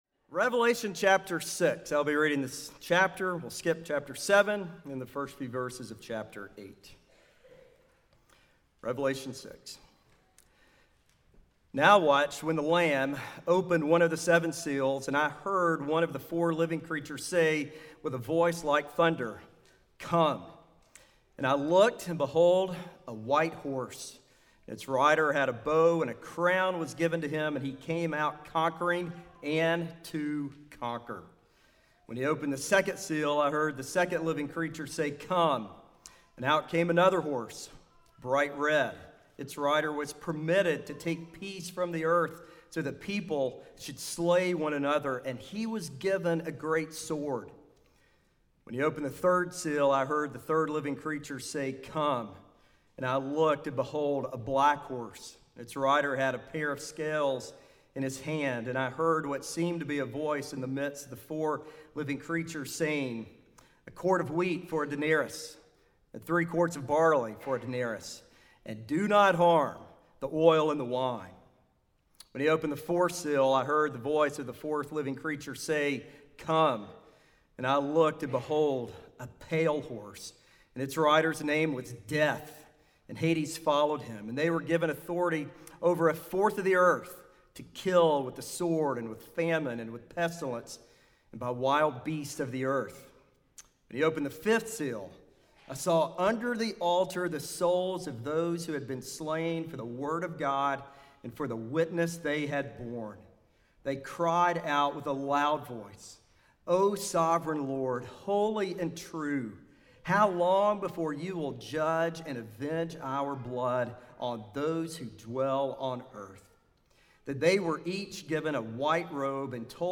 Sermons recorded during worship at Tates Creek Presbyterian Church (PCA) in Lexington, KY